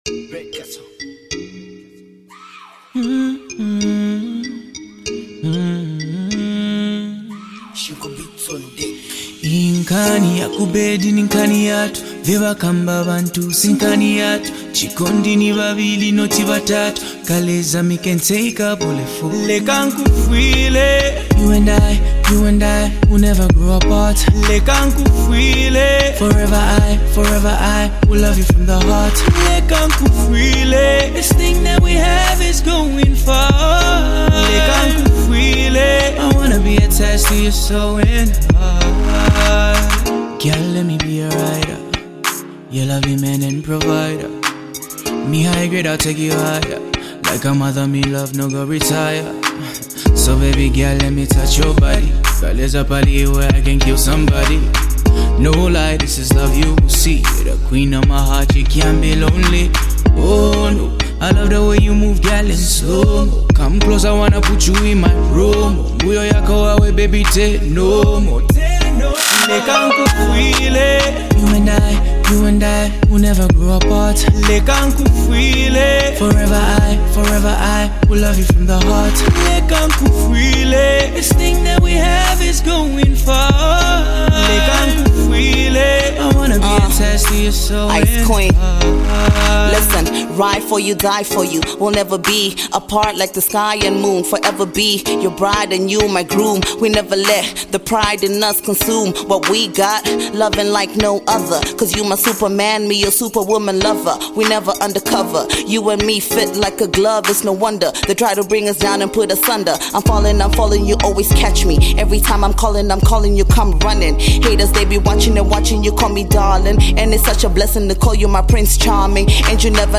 Gifted singer-song writer